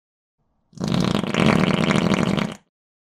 Download Free Fart Sound Effects | Gfx Sounds
Long-wet-squelchy-fart-4.mp3